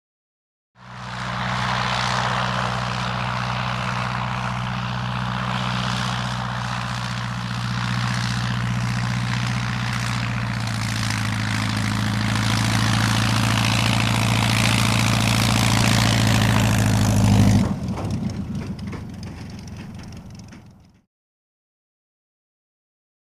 Prop Plane; Cut Engine; Fokker Single Engine Prop Aircraft Circa 1914 Up To Mic With High Revs, Then Dies Down With Rattles.